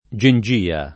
gengia [ J en J& a ]